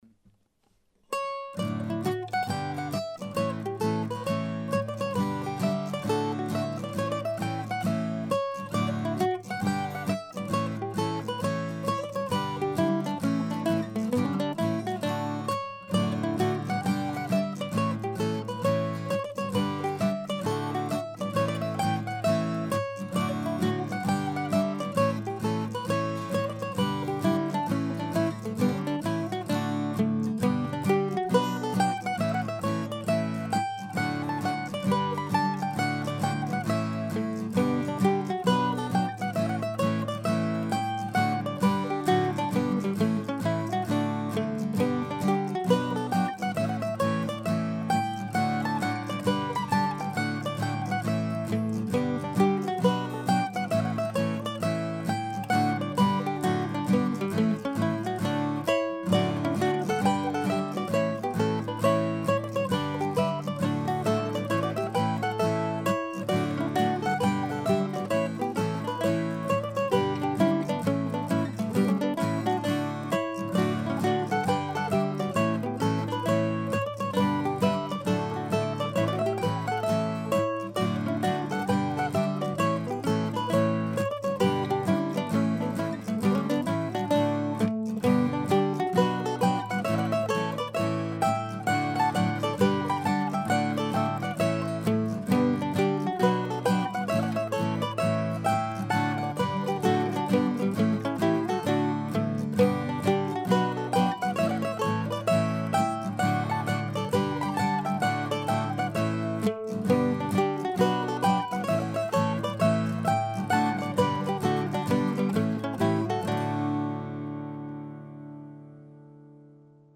I will benefit from this exposure for years to come in terms of inspiration but the immediate payoff came the next morning when today's featured tune popped out of wherever tunes come from into my fingers as I played my mandolin.